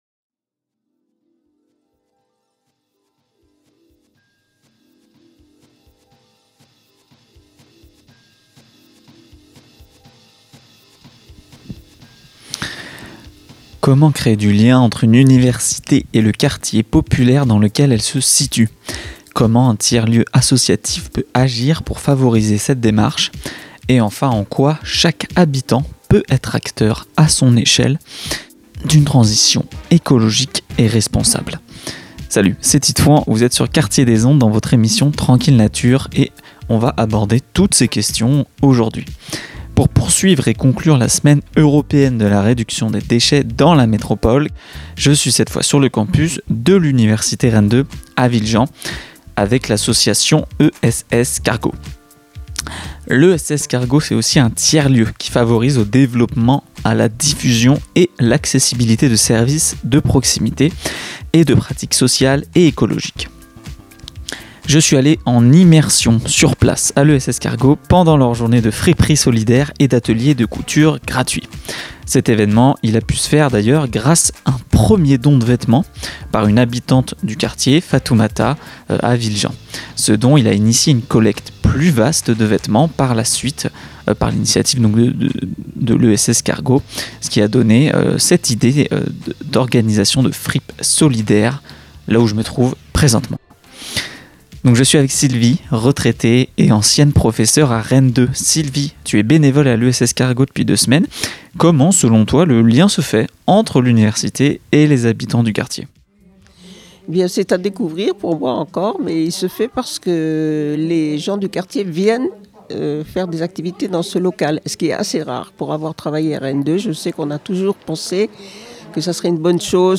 Semaine Européenne de Réduction des Déchets : Immersion en tiers-lieu associatif et solidaire à l'ESS CARGO, rencontre avec les bénévoles et membres de l'asso.